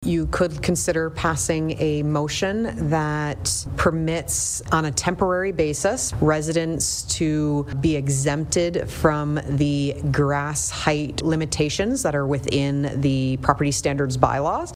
Renfrew Collegiate Institute sent a delegation to meet with town council on Tuesday night to discuss the concept of “No Mow May,” a movement where people are intentionally avoiding mowing their lawns for the benefit of the area’s wildlife and ecosystem.